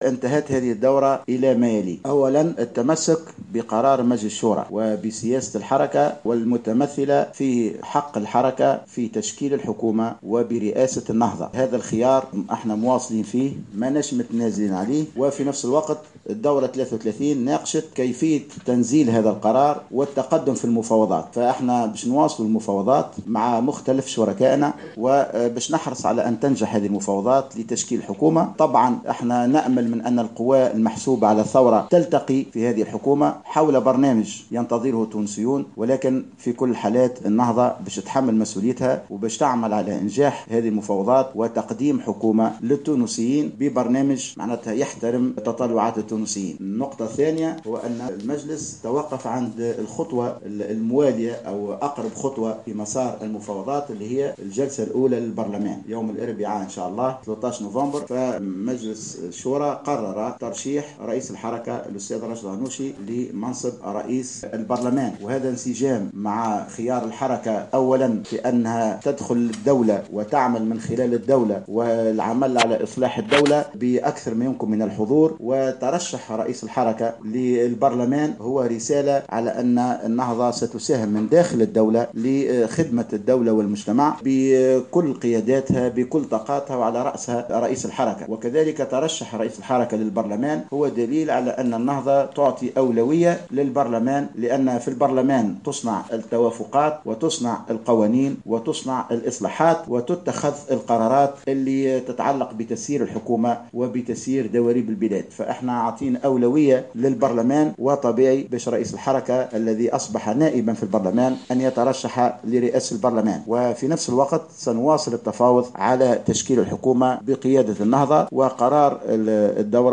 أكد عبد الكريم الهاروني رئيس مجلس شورى حركة النهضة في ندوة صحفية في تونس العاصمة، اليوم الأحد تمسّك الحركة بقرار ترشيح شخصية من داخلها لرئاسة الحكومة.